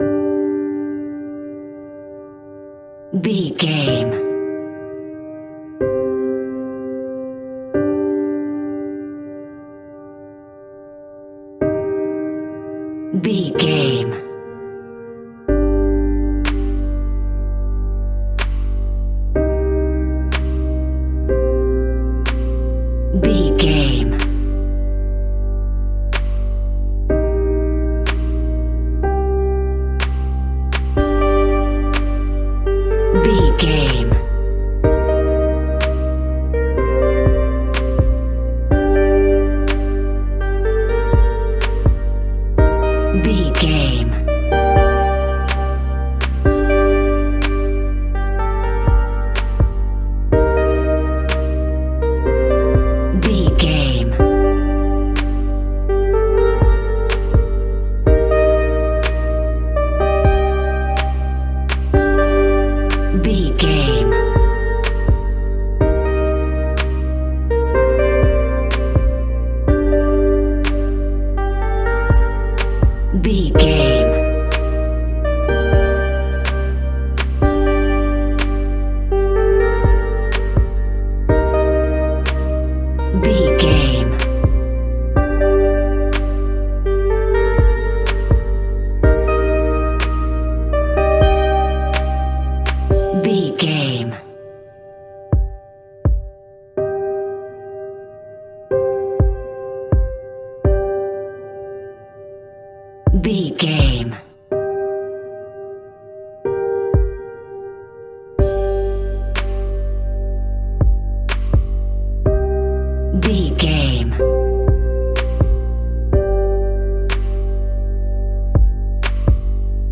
Aeolian/Minor
Slow
tranquil
synthesiser
drum machine